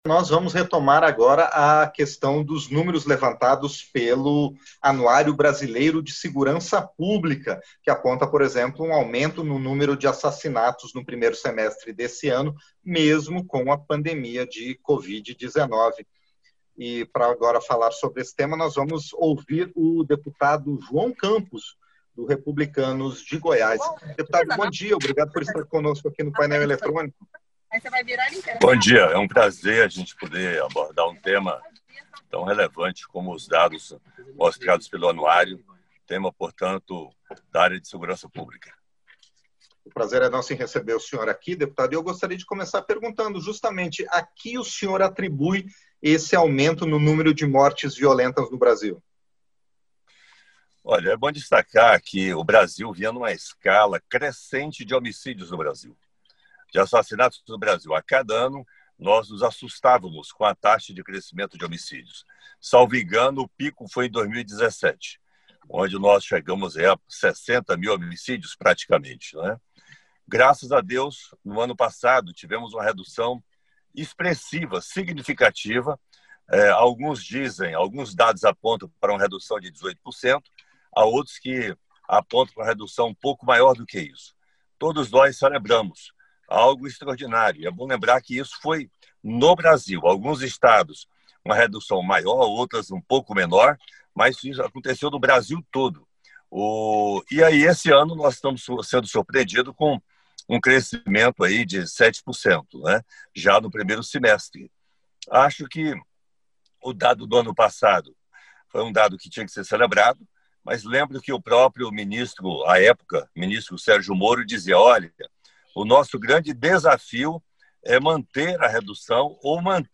Entrevista - Dep. João Campos (Republicanos-GO)